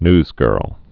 (nzgûrl, nyz-)